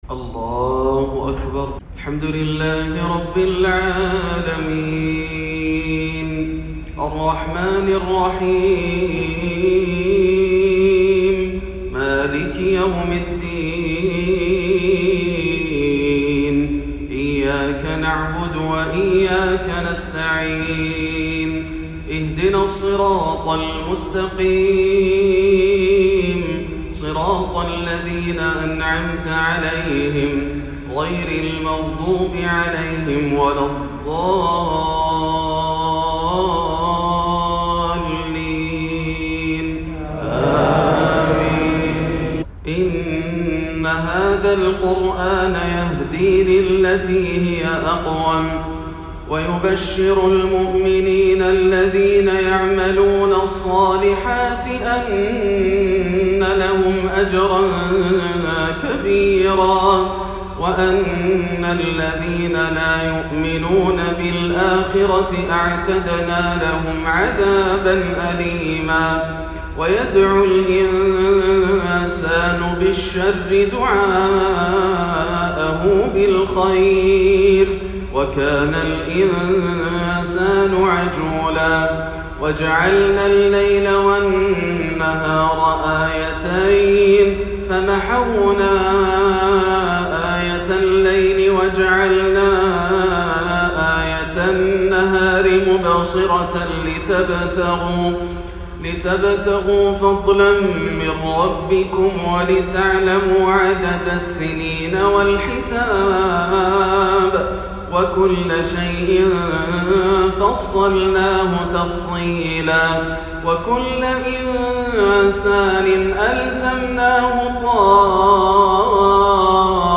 من أخشع التلاوات وأمتعها من سورة الإسراء وأواخر سورة الواقعة - عشاء الأربعاء 2-8 - من جامع التقوى > عام 1439 > الفروض - تلاوات ياسر الدوسري